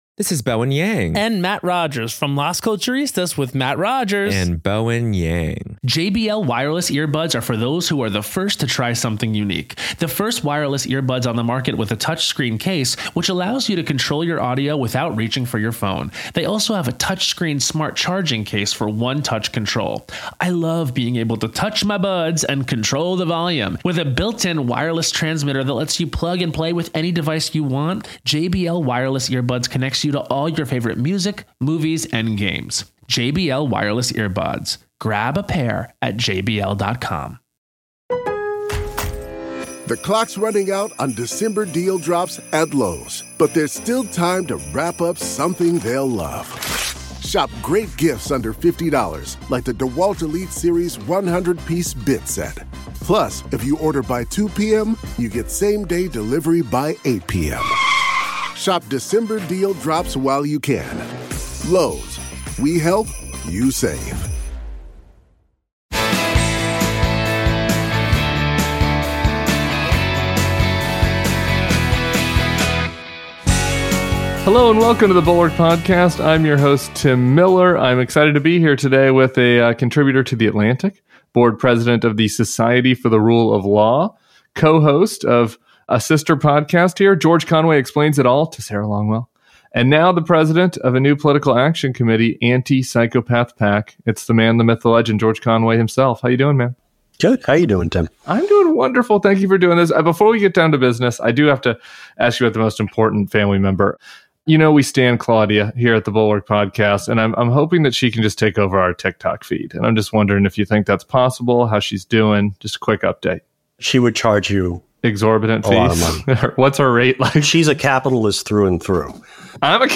Conway joins Tim Miller for the weekend pod.